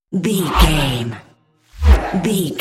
Whoosh electronic metal fast
Sound Effects
Atonal
Fast
futuristic
intense